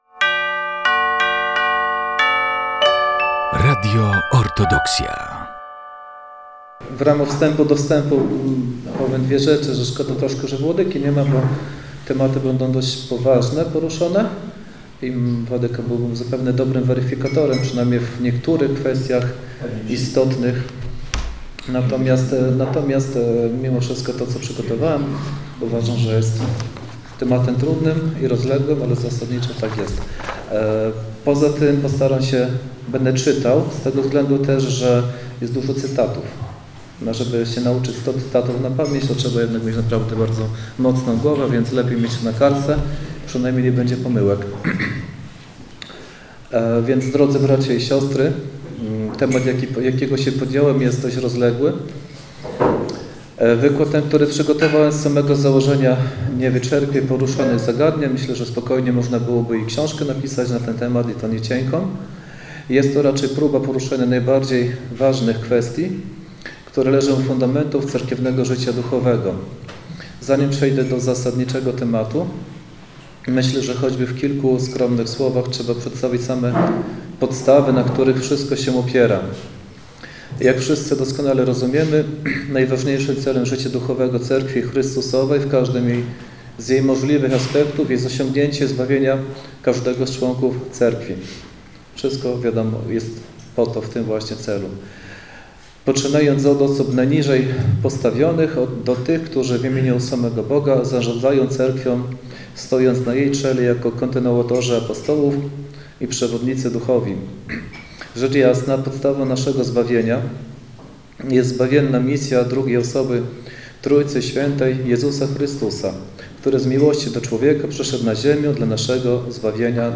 Wykład został nagrany 20 listopada 2024 roku w ramach cyklu Supraskie Środy.